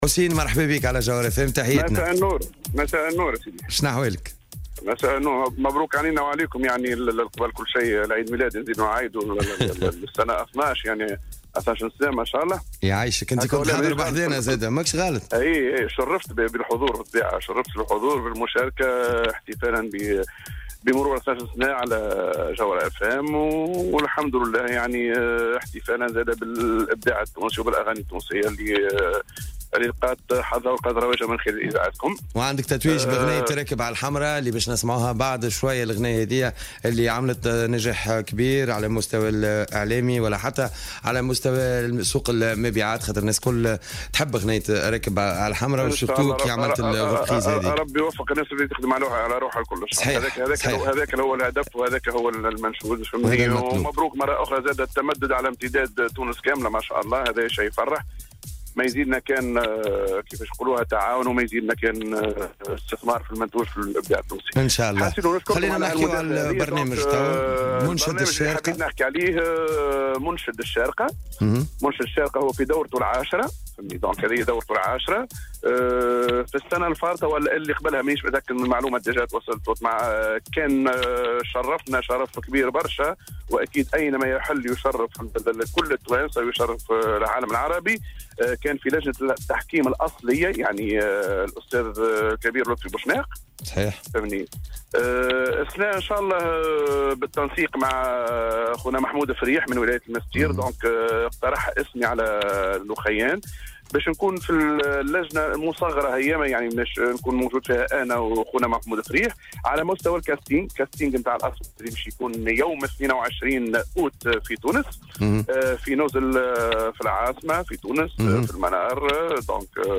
وأوضح في مداخلة له اليوم في برنامج " "Jawhara By Night" أن "الكاستينغ" الخاص بهذا البرنامج سيكون يوم 22 أوت الحالي في إحدى الوحدات الفندقية بتونس العاصمة بداية من الساعة الثامنة صباحا لاكتشاف مواهب الإنشاد الديني.